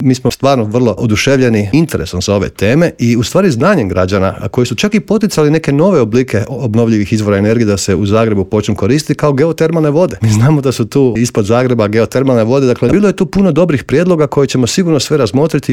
ZAGREB - Povodom međunarodne Konferencije "Zelena tranzicija pokreće europske gradove" predsjednik Gradske skupštine Grada Zagreba Joško Klisović gostovao je Intervjuu Media servisa.